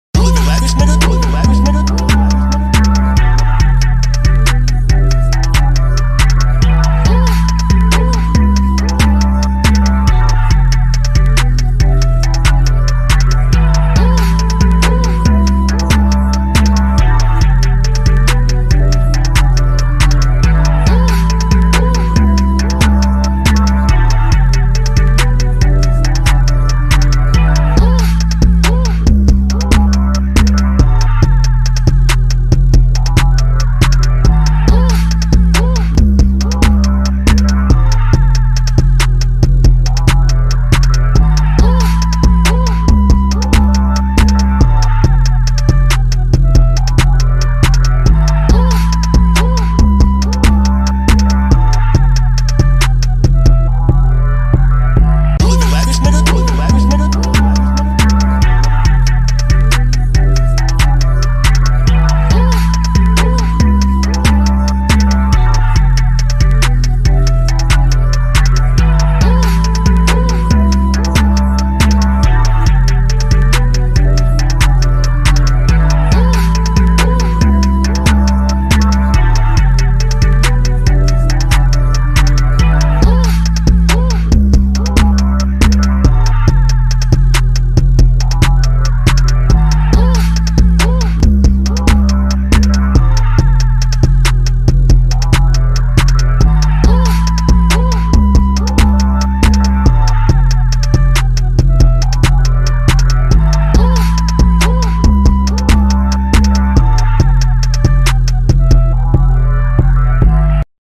official instrumental
2024 in Hip-Hop Instrumentals